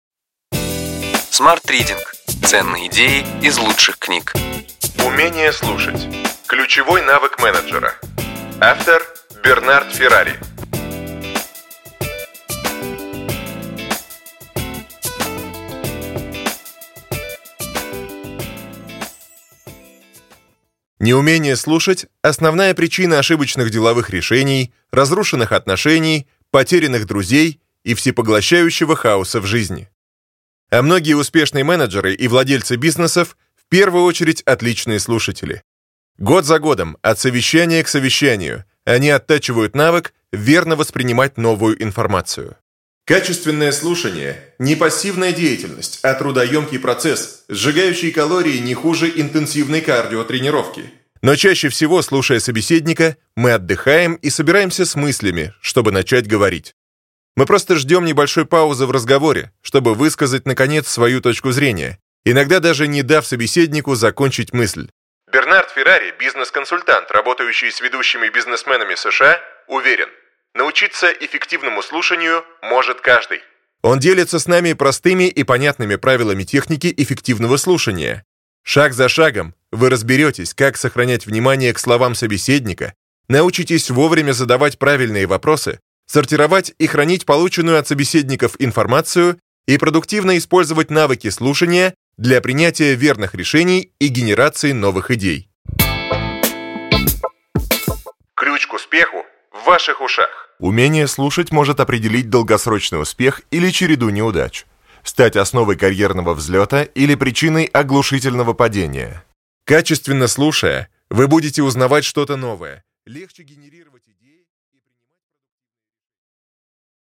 Аудиокнига Ключевые идеи книги: Умение слушать. Ключевой навык менеджера.